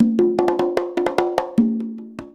100 CONGAS12.wav